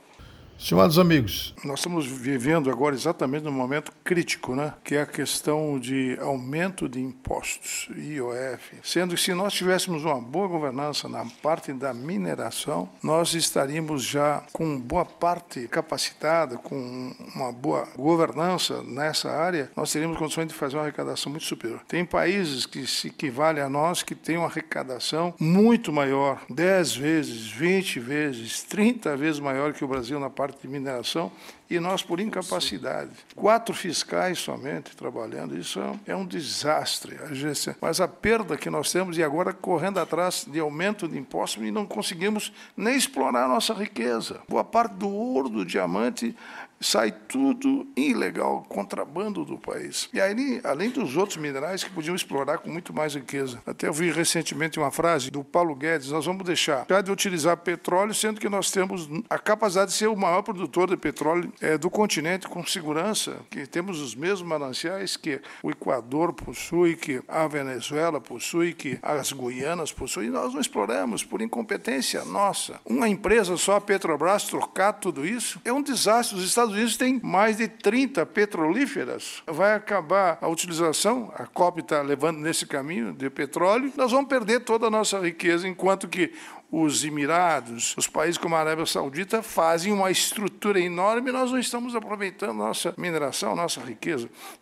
Comentário do ministro Augusto Nardes do Tribunal de Contas da União.